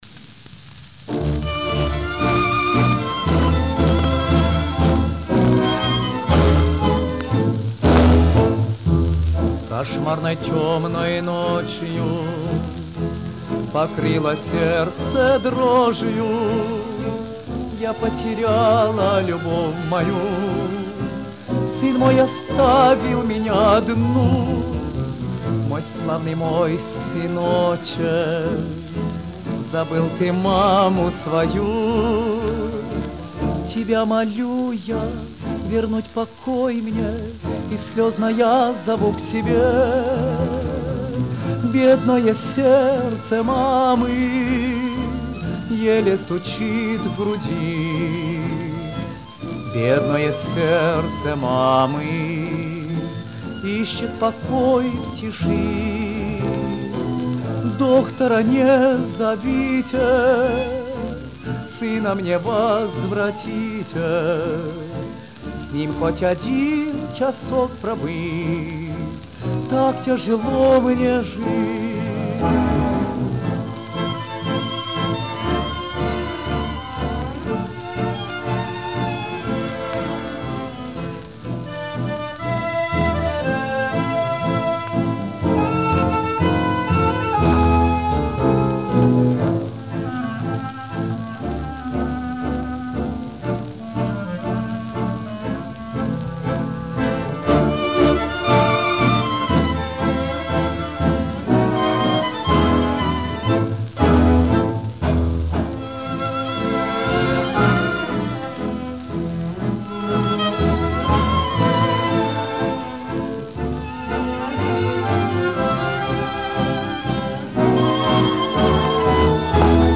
Жанр: Танго
Вид аккомпанемента:Оркестр
Место записи:Бухарест